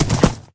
gallop1.ogg